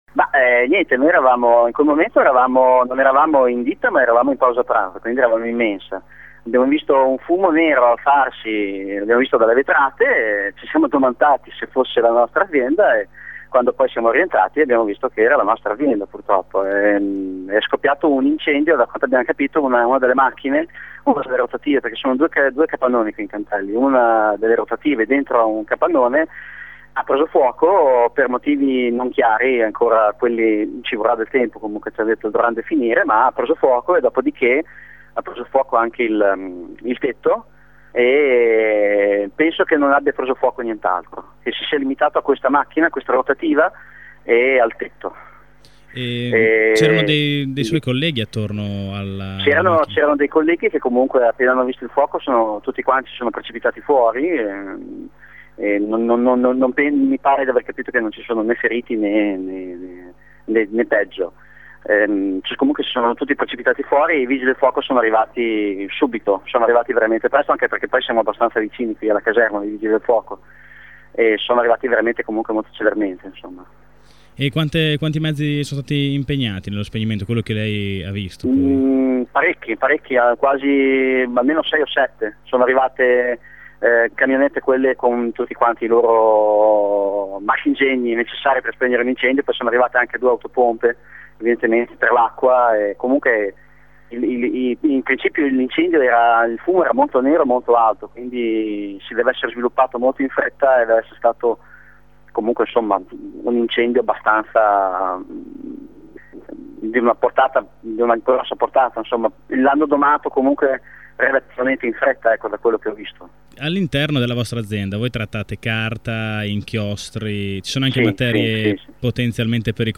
Ascolta la testimonianza di un lavoratore